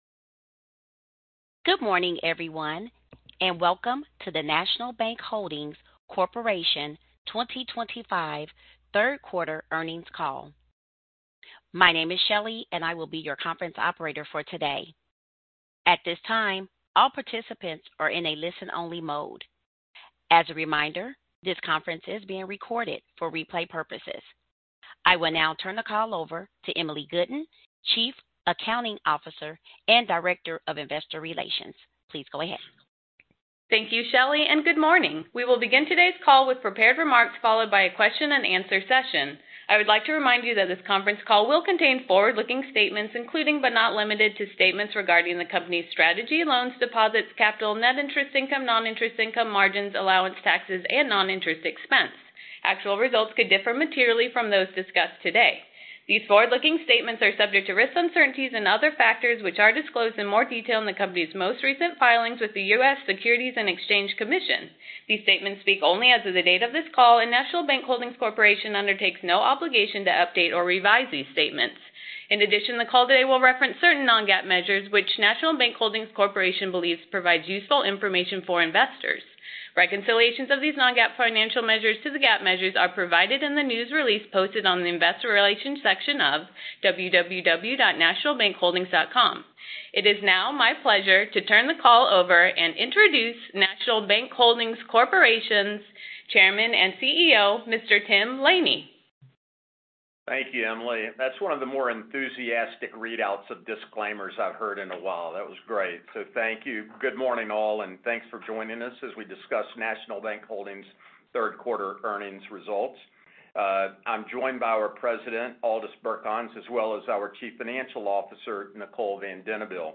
National Bank Holdings Corporation - Q3 Earnings Conference Call
NBHC_3rd-Qtr_Earnings-Call.mp3